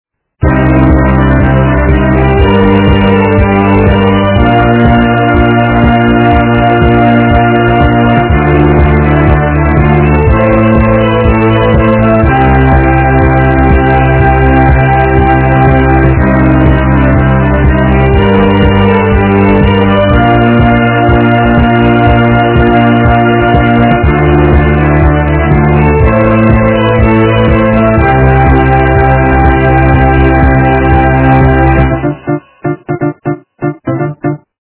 западная эстрада
качество понижено и присутствуют гудки